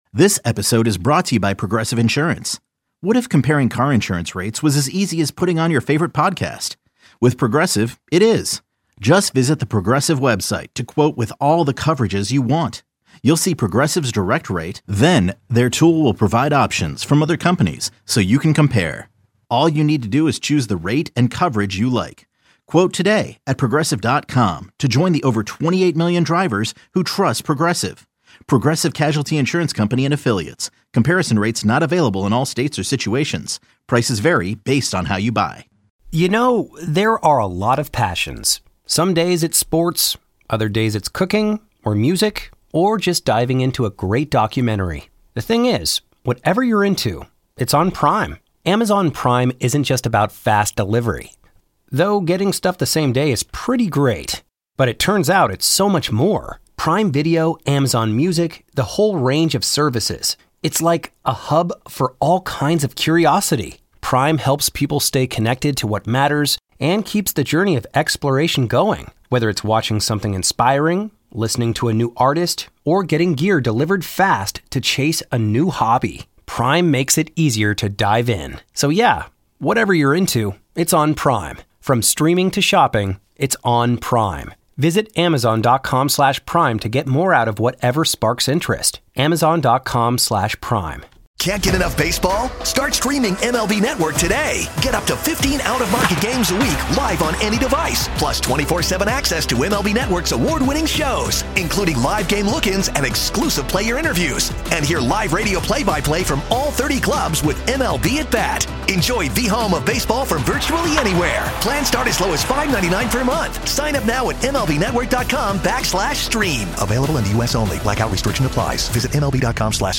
Callers Sound Off On The Knicks